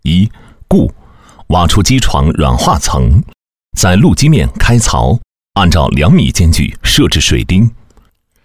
Professionell Röstinspelning för Ingenjörsguider | Tydlig Teknisk AI-berättarröst
Förmedla komplexa tekniska koncept med en tydlig, professionell AI-röst utformad för ingenjörsguider, industriella säkerhetsmanualer och arkitektoniska genomgångar.
Teknisk TTS
Exakt Artikulation
Auktoritativ Ton